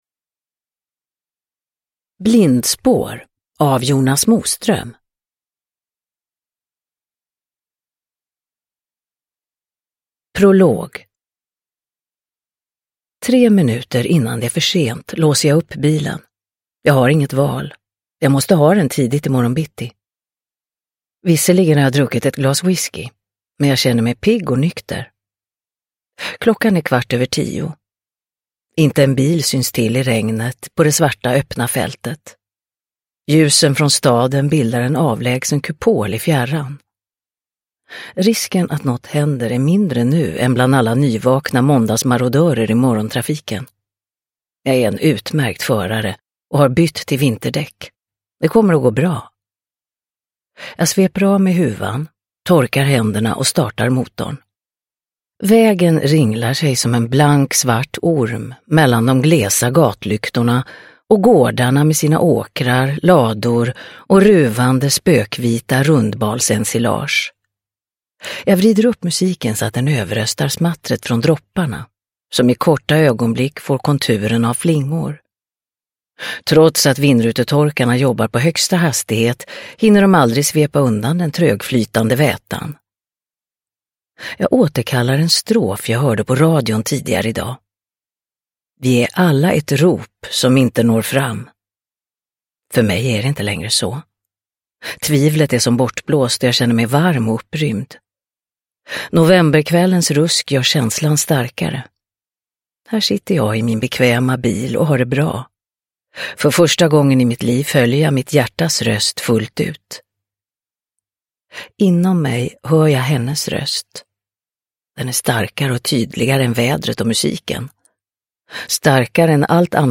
Blindspår – Ljudbok – Laddas ner
Uppläsare: Marie Richardson